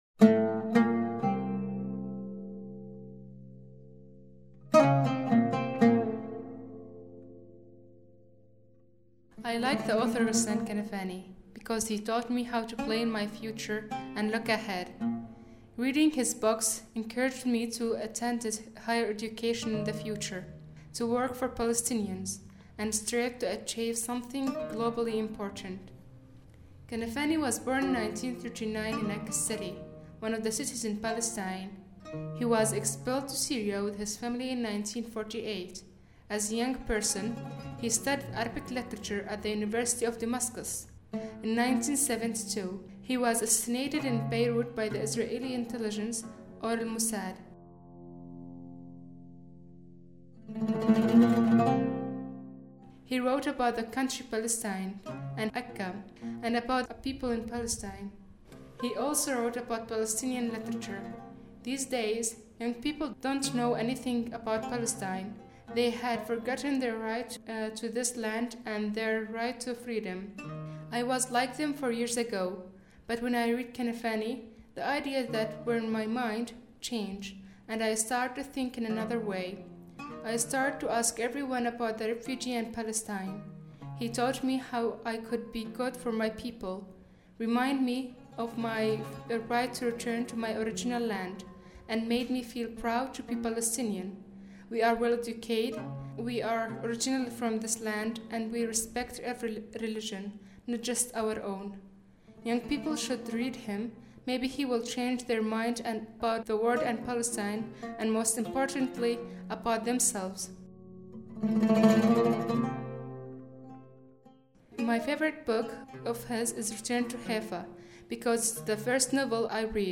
Music by Le Trio Joubran